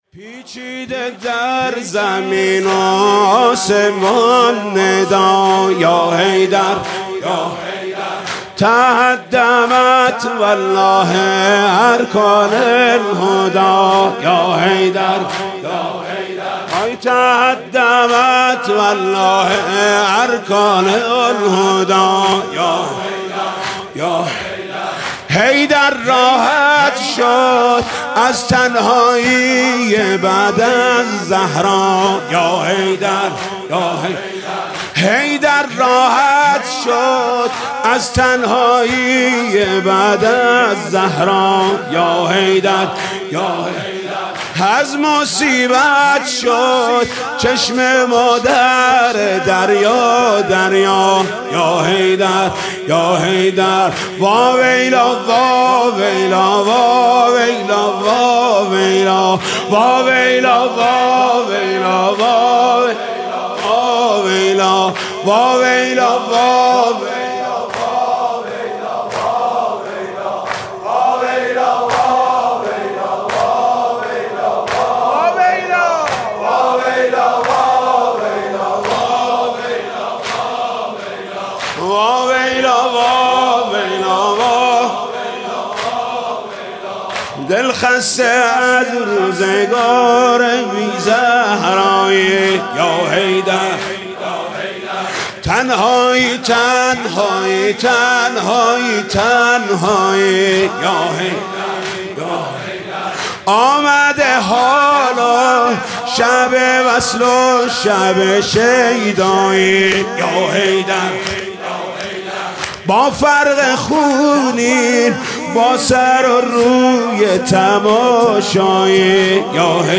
«شب نوزدهم» زمینه: پیجیده در آسمان و زمین ندا